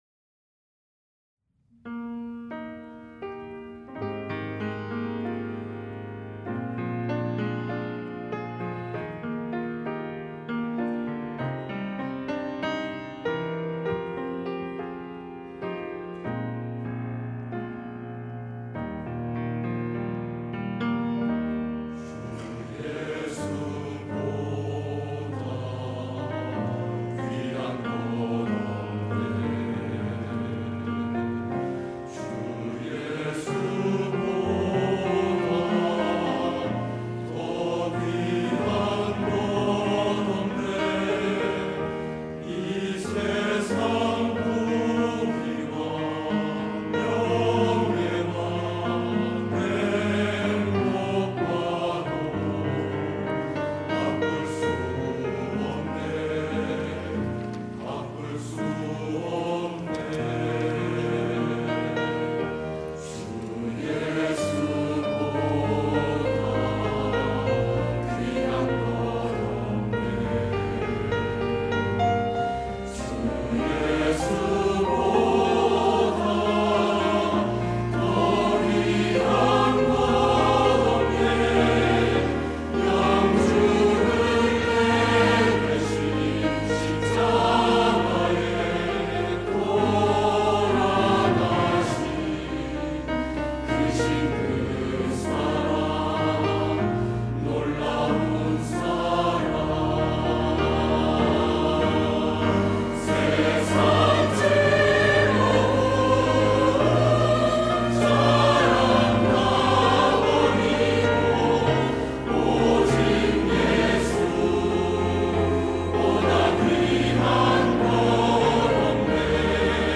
할렐루야(주일2부) - 주 예수보다 더 귀한 것은 없네
찬양대